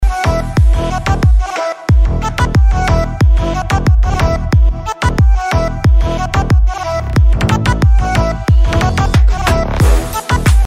Slap House Bass